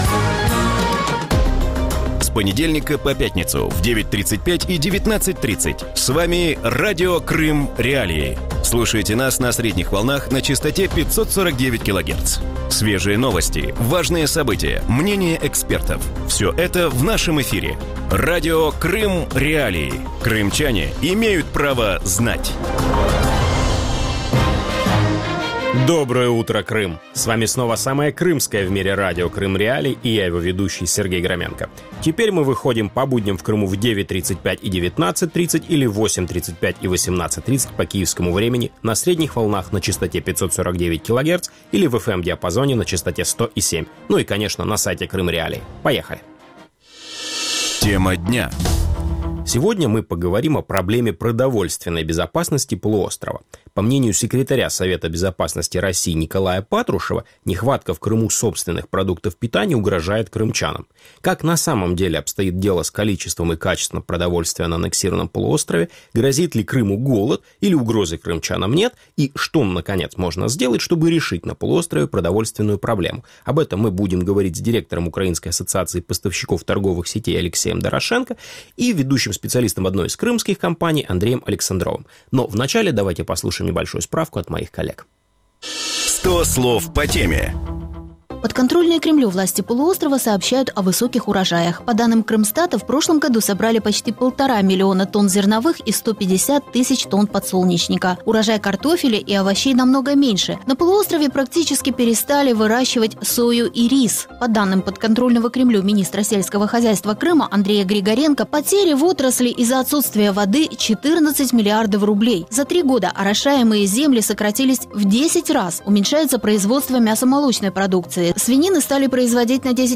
Вранці в ефірі Радіо Крим.Реалії говорять про проблему продовольчої безпеки півострова.